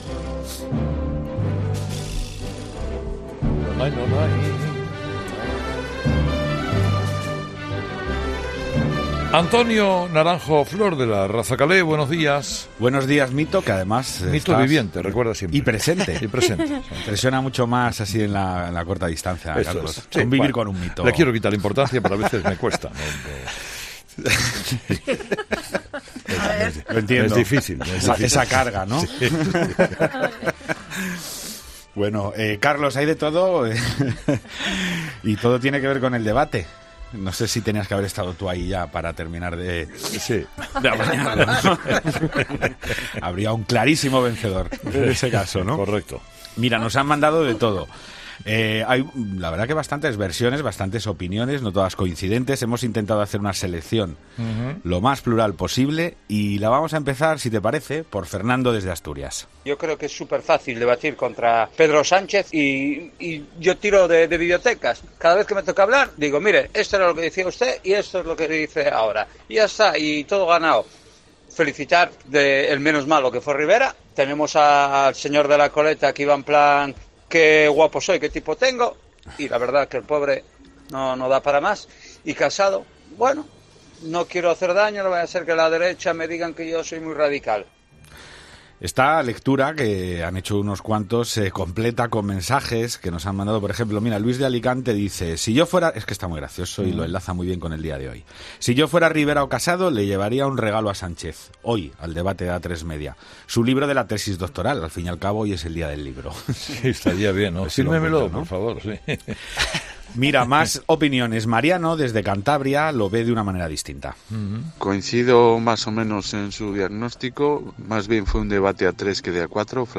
La tertulia de los oyentes se ha centrado hoy en el primero de los dos únicos debates electorales de toda la campaña.